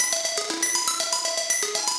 SaS_Arp01_120-E.wav